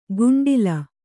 ♪ guṇḍila